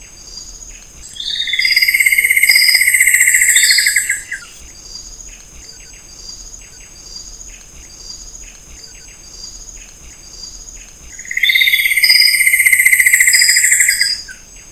Arapaçu-de-garganta-amarela (Xiphorhynchus guttatus)
Nome em Inglês: Buff-throated Woodcreeper
Fase da vida: Adulto
Detalhada localização: Mata da Palatéia
Condição: Selvagem
Certeza: Gravado Vocal
Arapacu-de-garganta-amarela.mp3